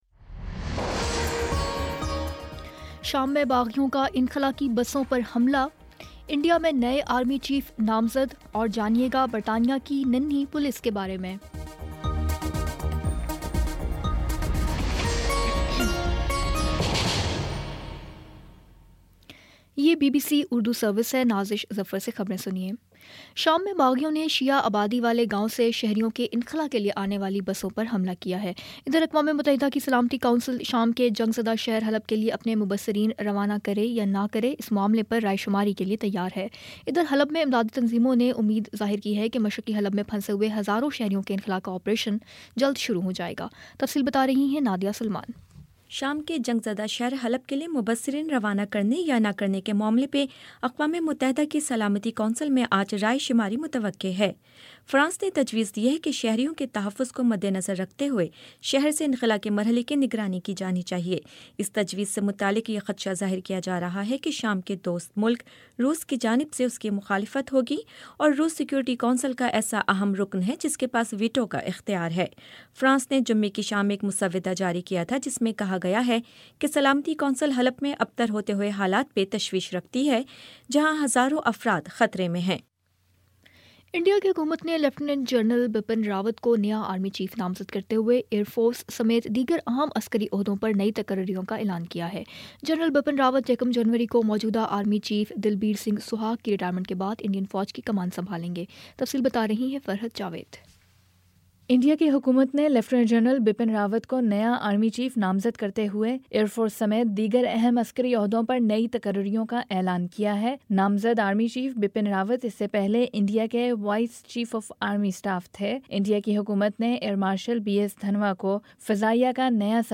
دسمبر 18 : شام سات بجے کا نیوز بُلیٹن